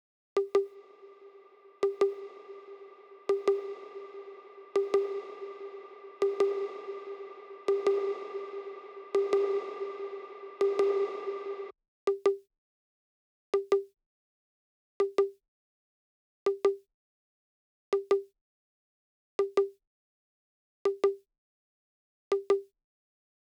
CONGA RISER.wav